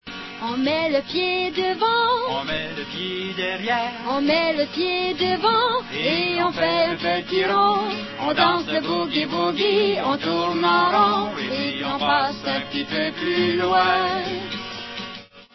Danses